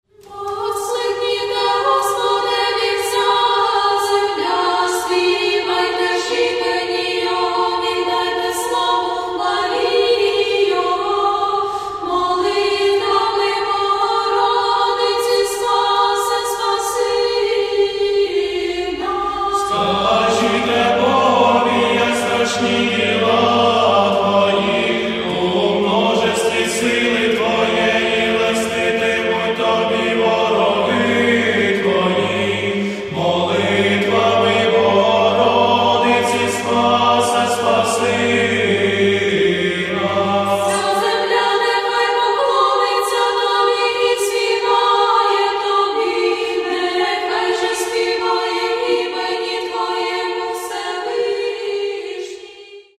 Церковна